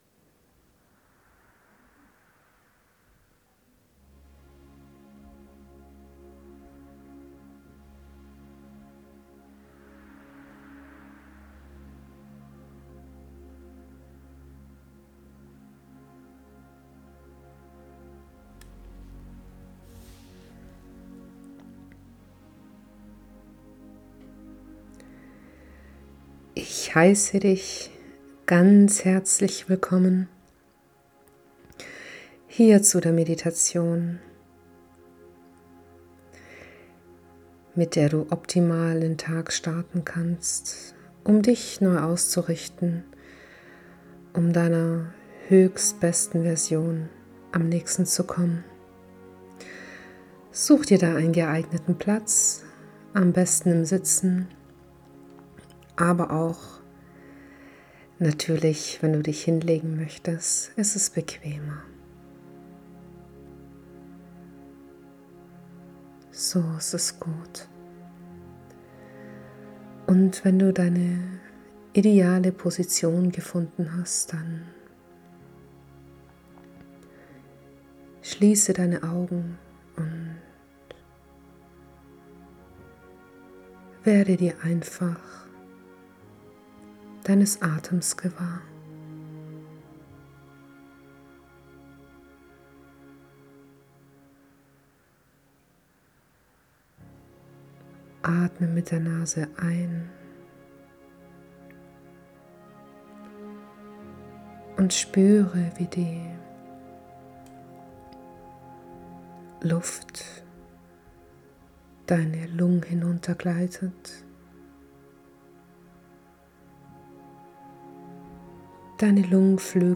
Es ist eine Morgen-Meditation und perfekt dafür geeignet voller Zuversicht und Selbstliebe in den Tag zu starten!